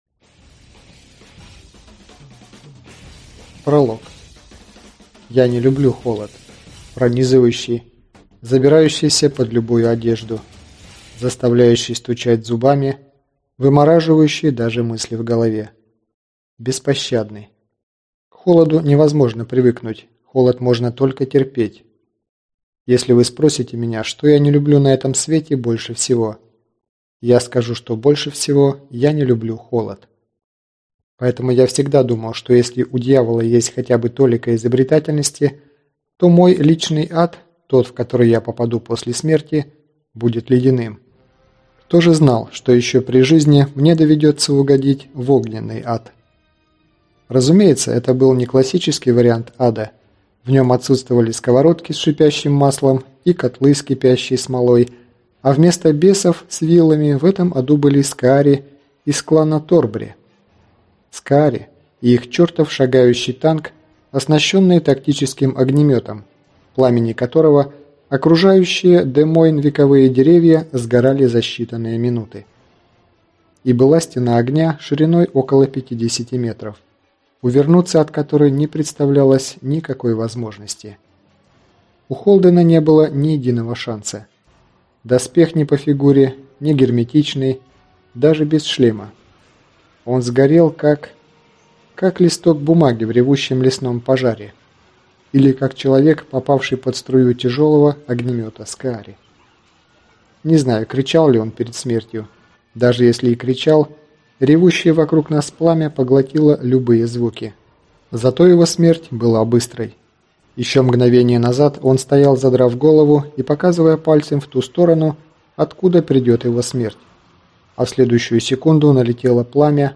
Аудиокнига Война неудачников | Библиотека аудиокниг